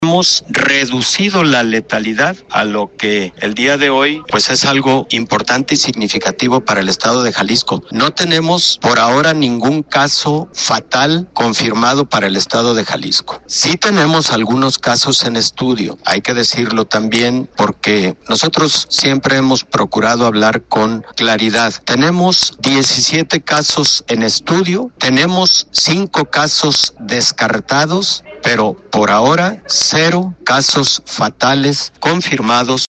El secretario de salud de Jalisco Héctor Raúl Pérez Gómez dio a conocer que el dengue es un problema de salud pública en Jalisco y en todo el continente americano, en Jalisco se detienen registrados 837 casos de dengue sin ninguna defunción